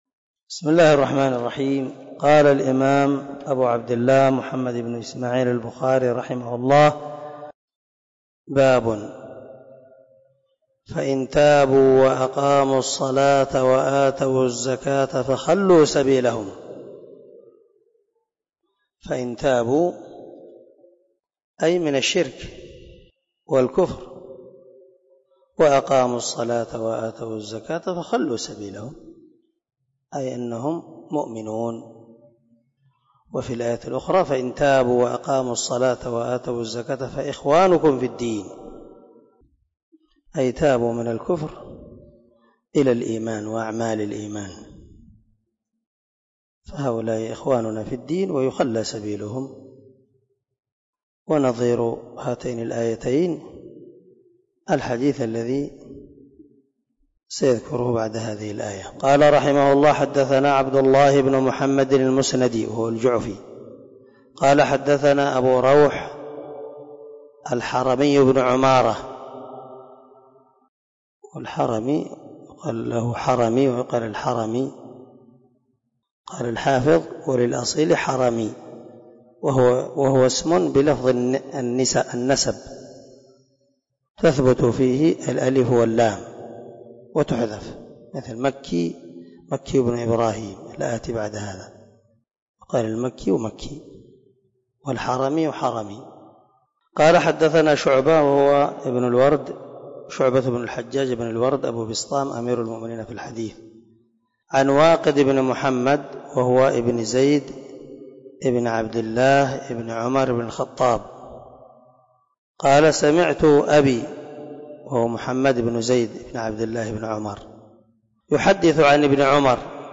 026الدرس 16 من شرح كتاب الإيمان حديث رقم ( 25 ) من صحيح البخاري